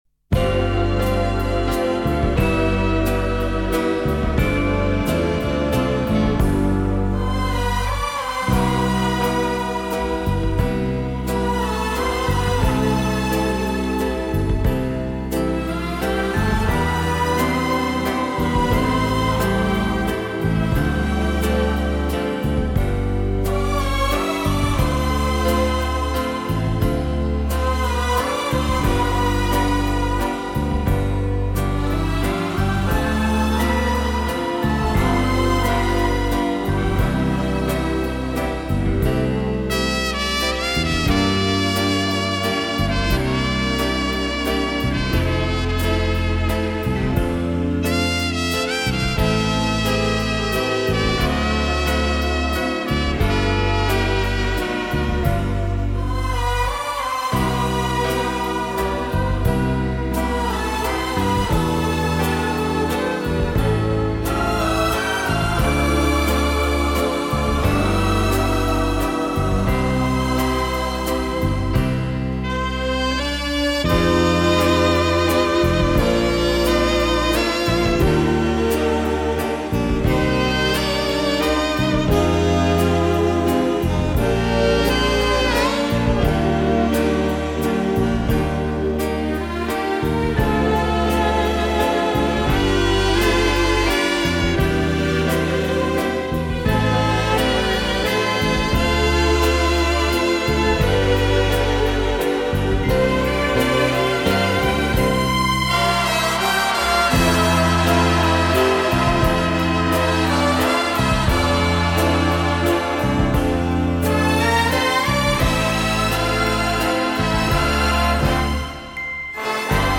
Genre:Easy Listening
(Waltz 29)
(Waltz)